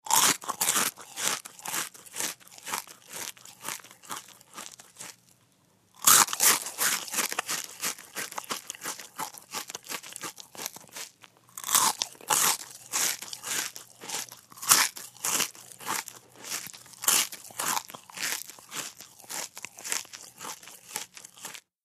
DryCerealBiteChew PE678401
DINING - KITCHENS & EATING DRY CEREAL: INT: Bite into, medium paced dry chewing.